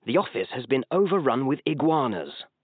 *⃣ Asterisk sound 'office-iguanas.wav'
🇬🇧 Spoken in British English